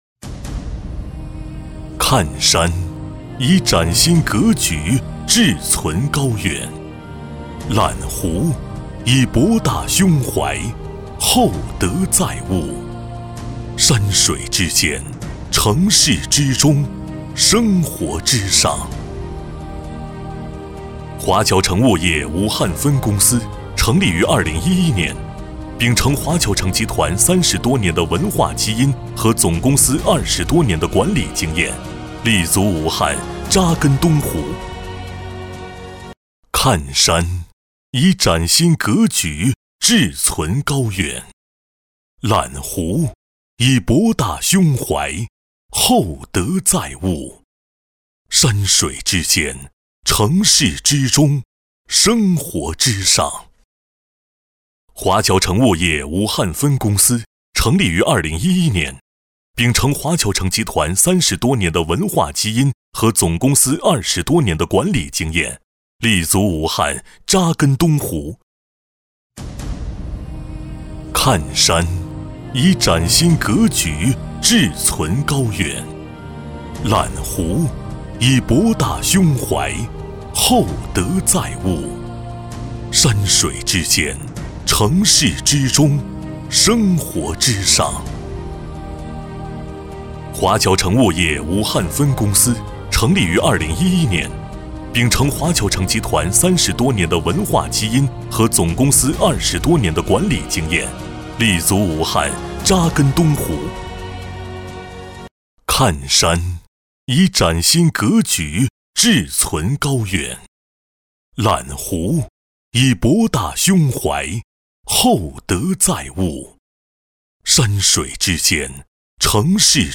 国语青年大气浑厚磁性 、沉稳 、男专题片 、宣传片 、60元/分钟男S310 国语 男声 专题片-华为-浑厚、大气 大气浑厚磁性|沉稳